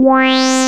RESO SYNTH.wav